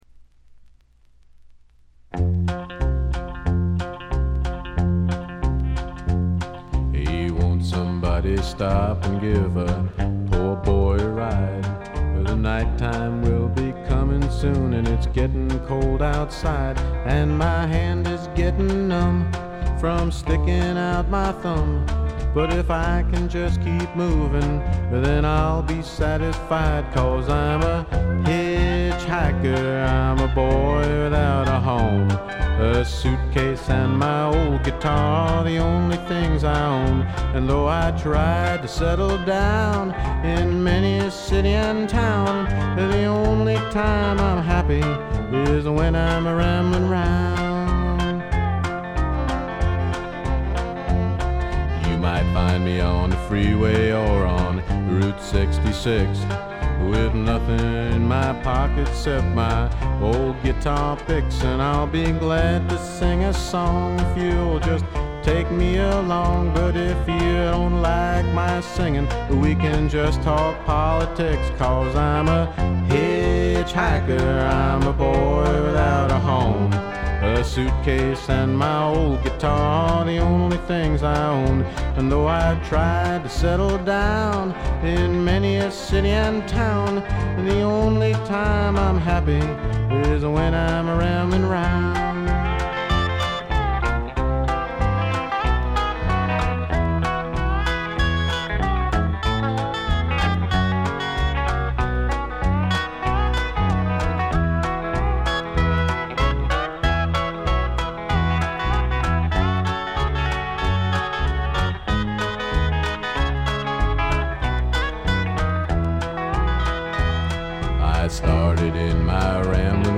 これ以外は軽微なチリプチ少々で良好に鑑賞できると思います。
試聴曲は現品からの取り込み音源です。